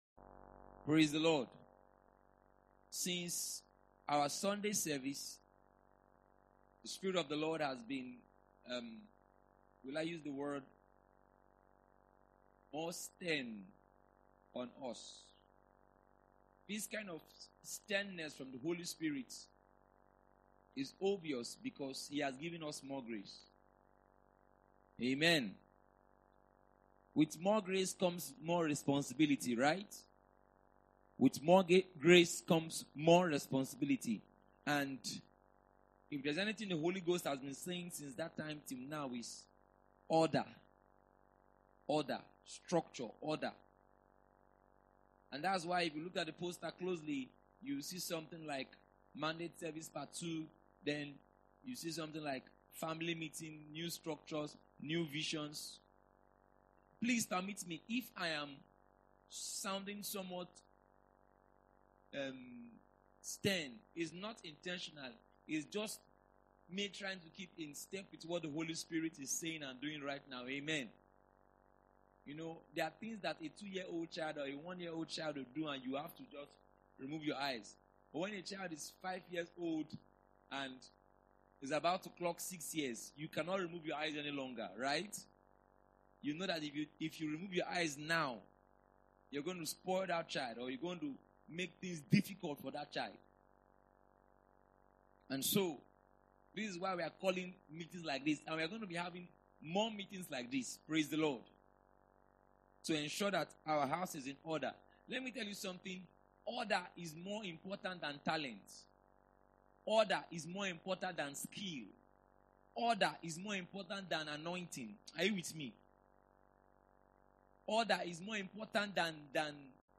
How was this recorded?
The Mandate Service 1.mp3